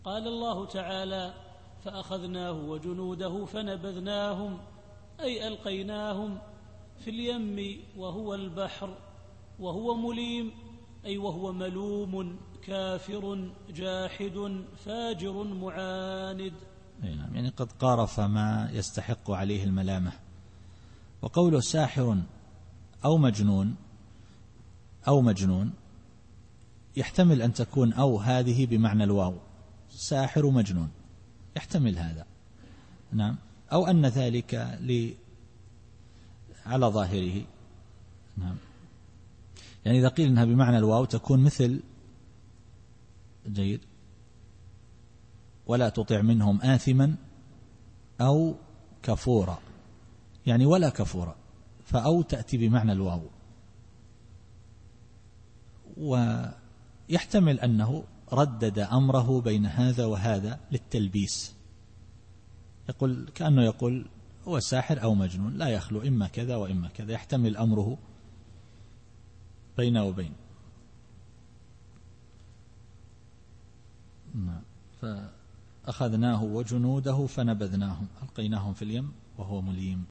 التفسير الصوتي [الذاريات / 40]